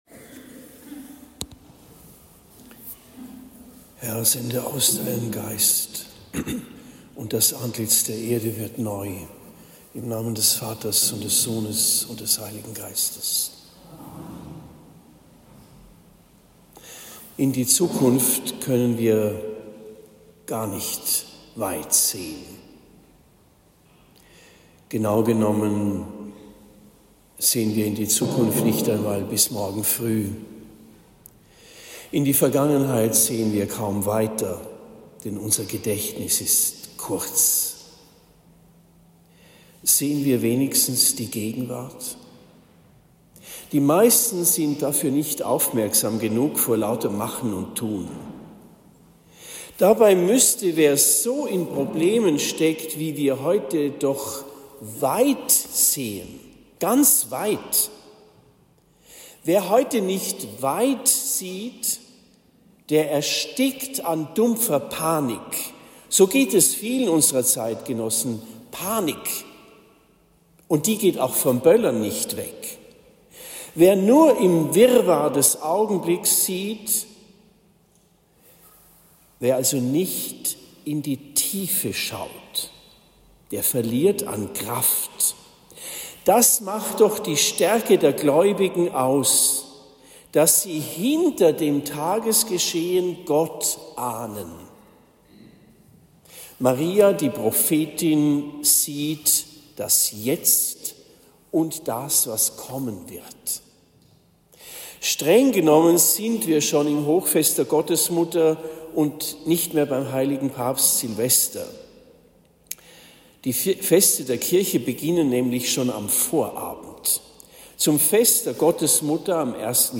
Fest des Heiligen Sylvester Predigt in Esselbach am 31. Dezember 2025